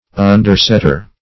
Search Result for " undersetter" : The Collaborative International Dictionary of English v.0.48: Undersetter \Un"der*set`ter\, n. One who, or that which, undersets or supports; a prop; a support; a pedestal.